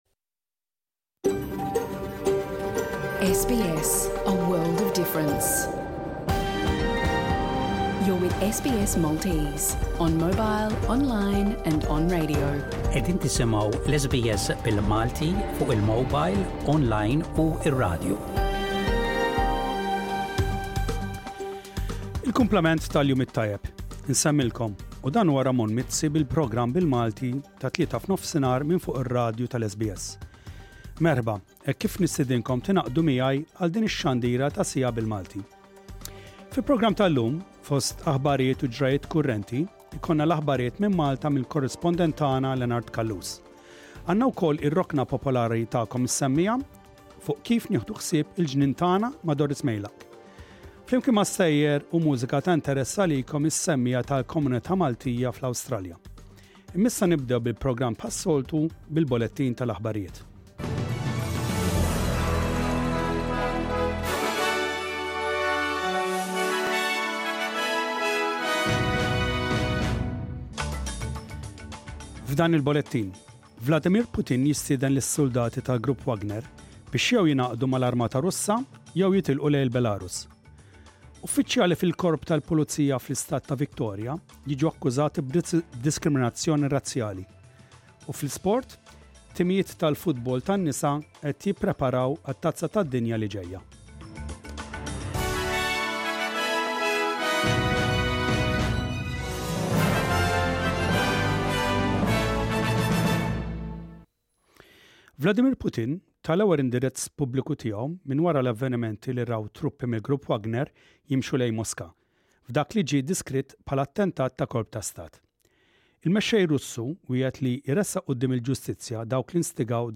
The Maltese Radio Programme Credit